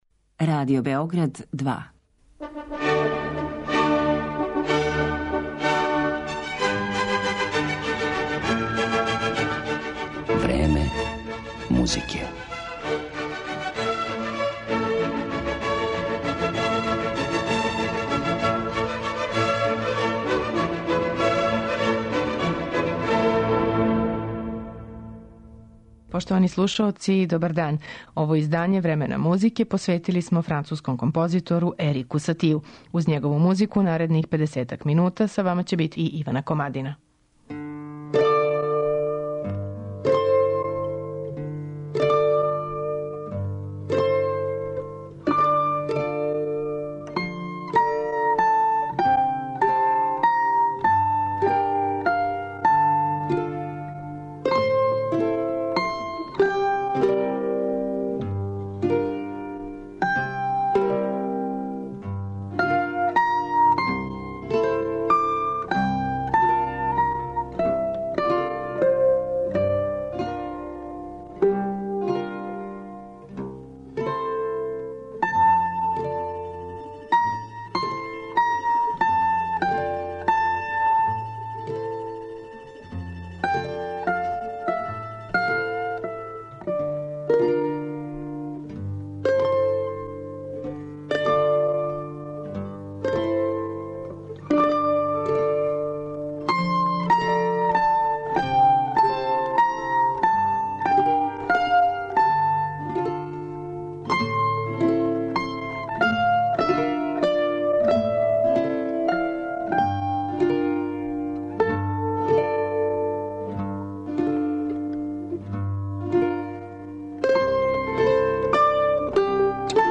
Клавирске композиције на мандолинама